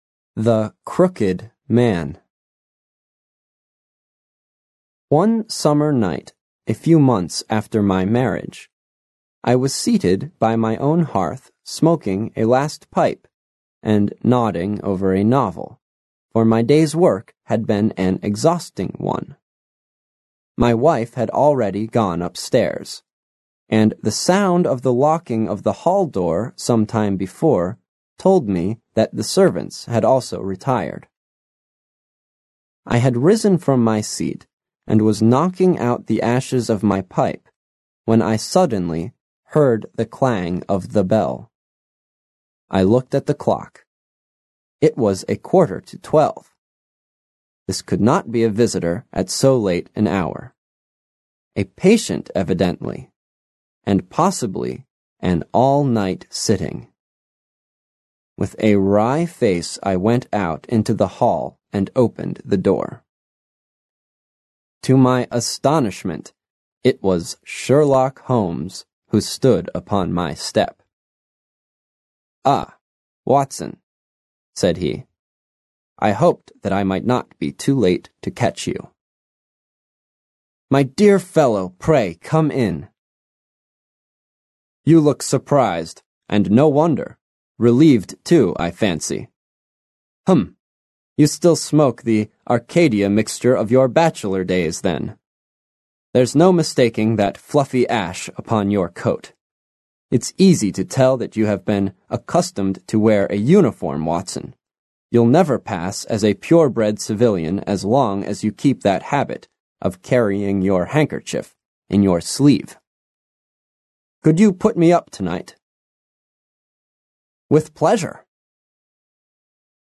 Аудиокнига Memoirs of Sherlock Holmes | Библиотека аудиокниг